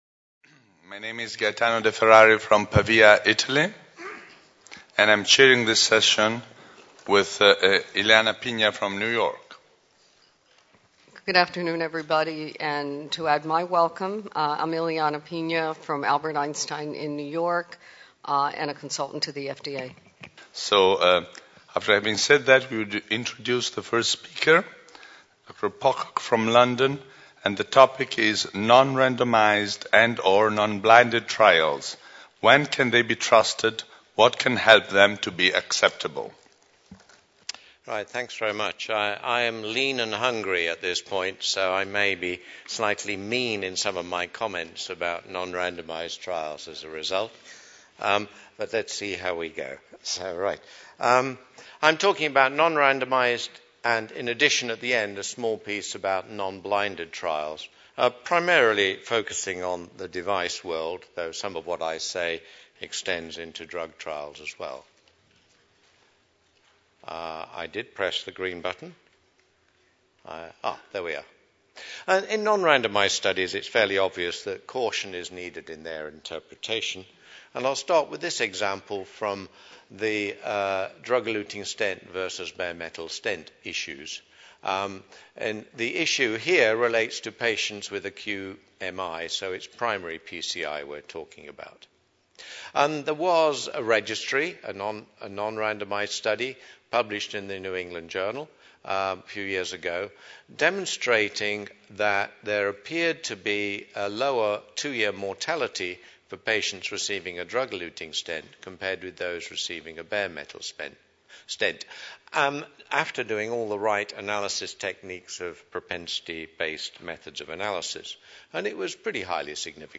Cardiovascular Clinical Trialists (CVCT) Forum – Paris 2012 - Lunch Session 1 : Non randomized and/or non-blinded trials